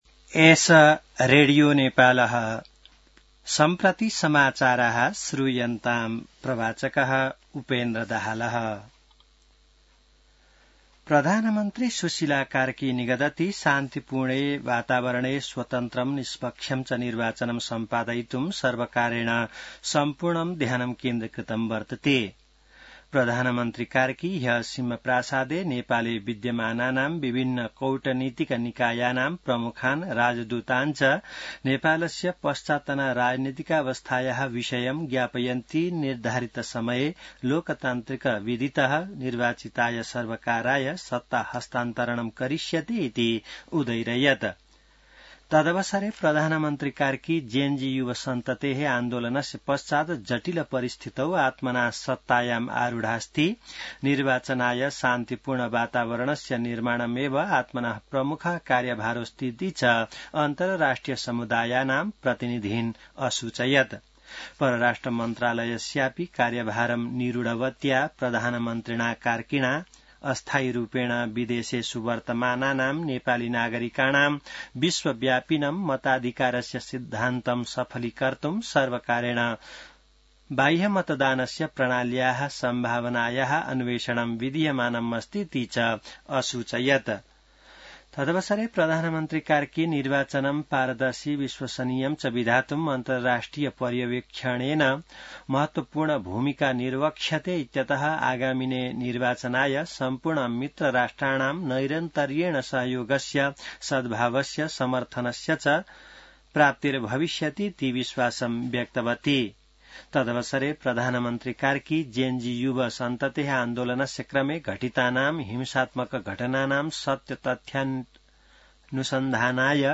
संस्कृत समाचार : १ कार्तिक , २०८२